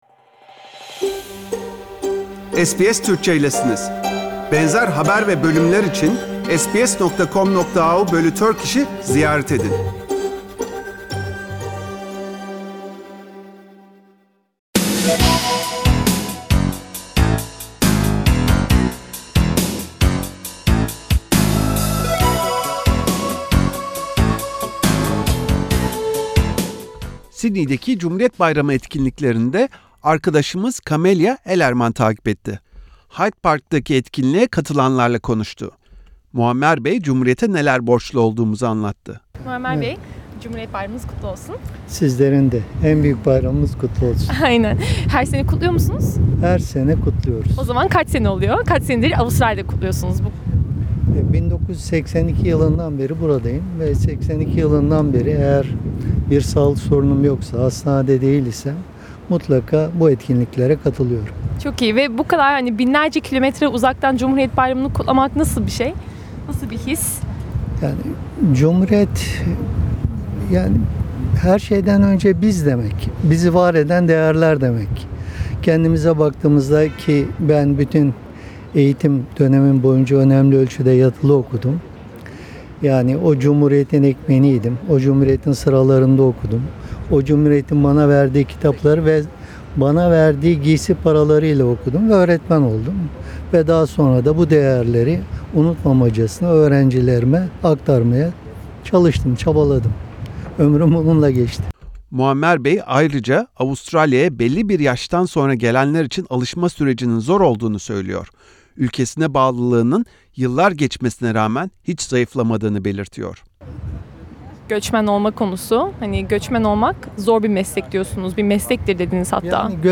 Sydney'deki Türk toplumunun bir kısmı, ünlü Hyde Park'ta cumhuriyetin kuruşulunun 96'ncı yıldönümünü kutladı.